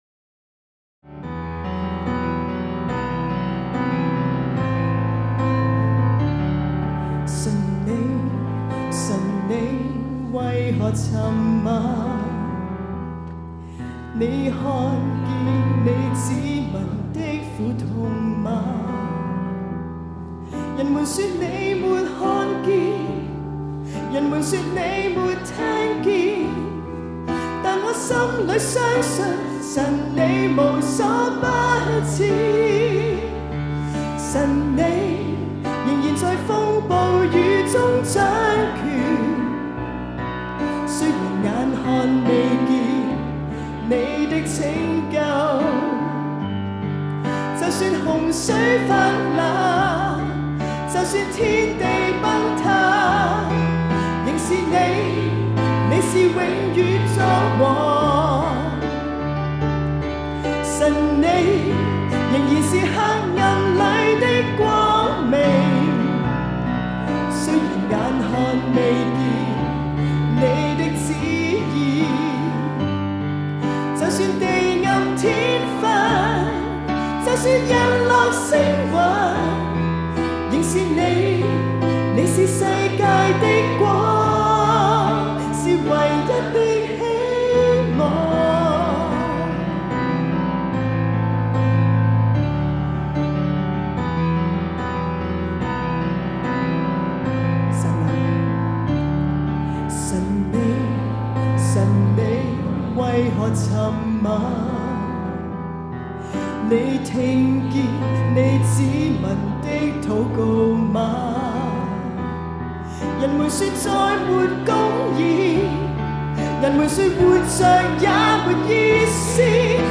Key G, Tempo 72
現場敬拜